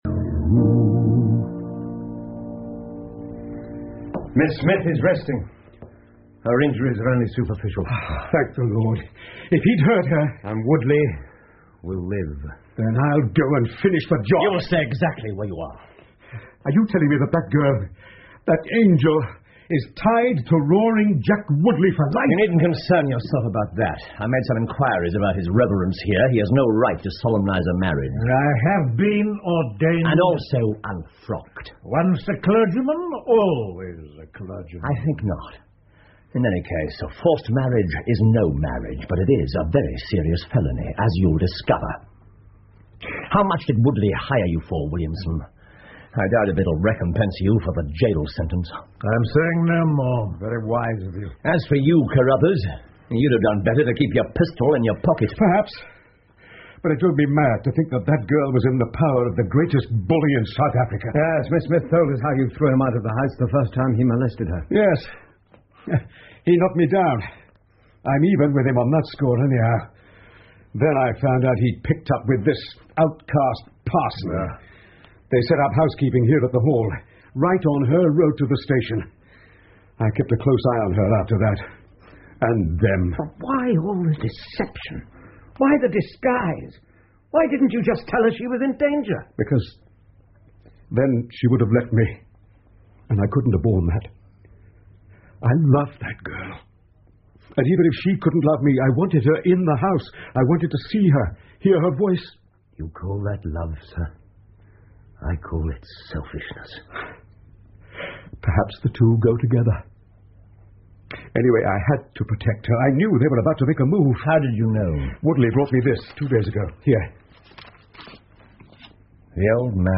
福尔摩斯广播剧 The Solitary Cyclist 8 听力文件下载—在线英语听力室